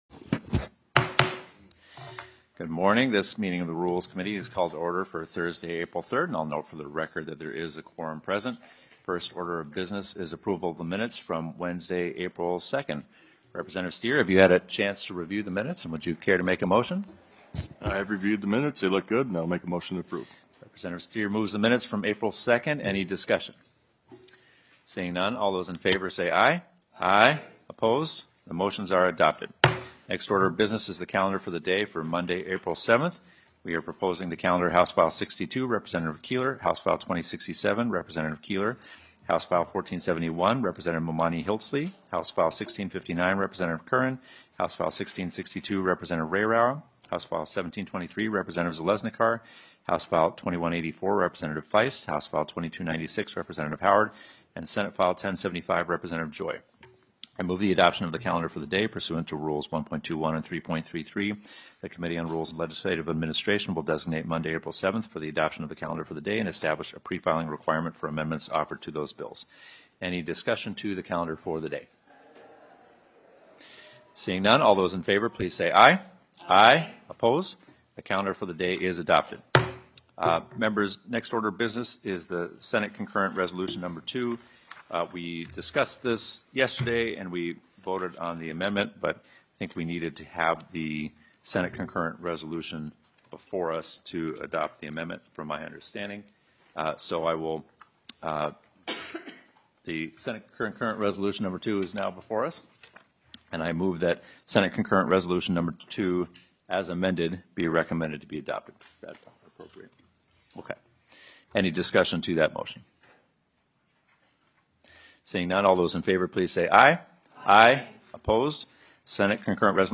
Representative Jamie Long, Co-Chair of the Committee on Rules and Legislative Administration, called the meeting to order at 10:09 am on Thursday, April 3, 2025, in Capitol Room 123.